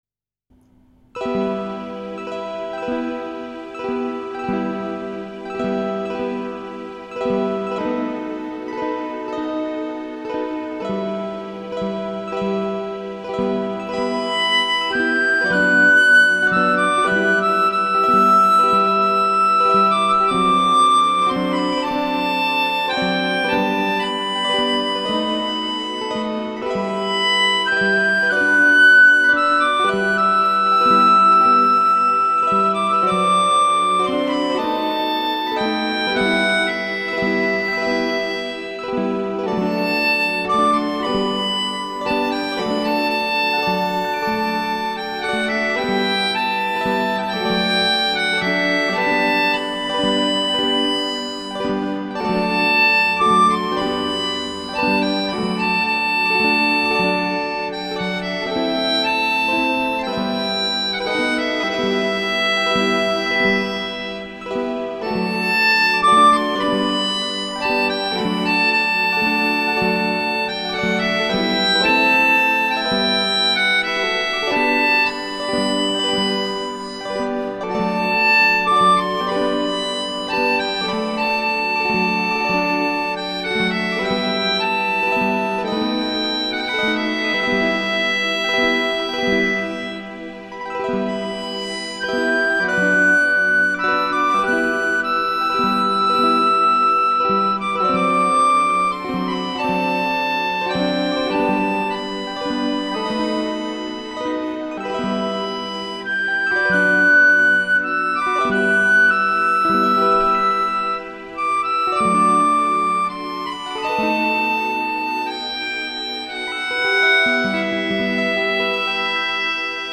Melodion
Harmonica
and Qchord